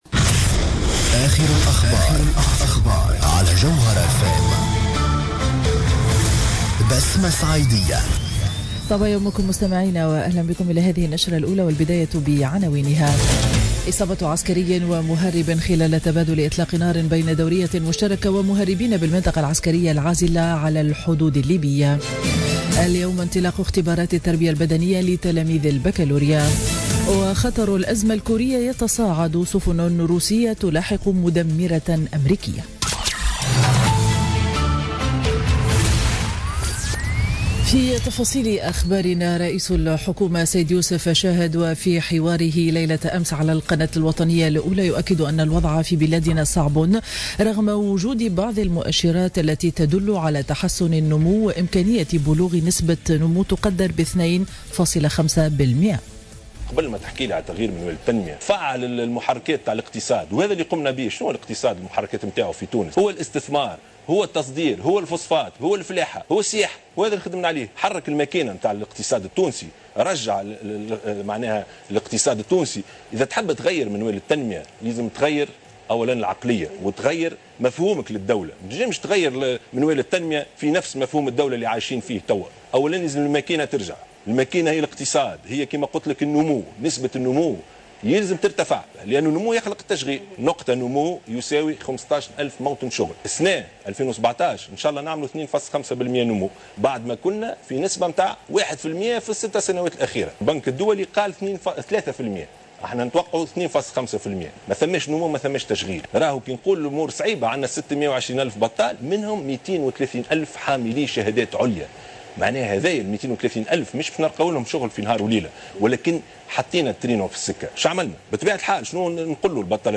نشرة أخبار السابعة صباحا ليوم الإثنين 17 أفريل 2017